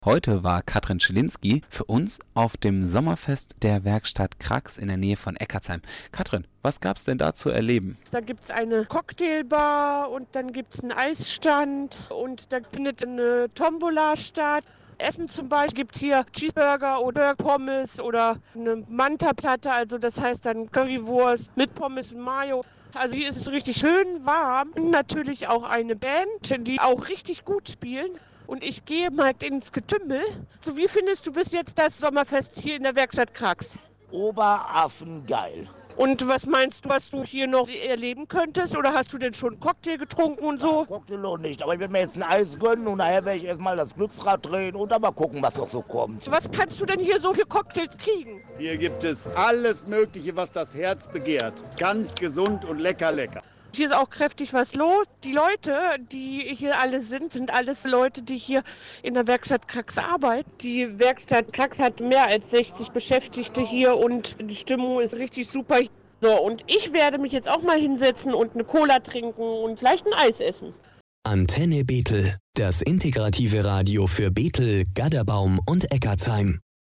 Sommerfest in der Werkstatt Kracks
Reportage-Sommerfest-Werkstatt-Kracks.mp3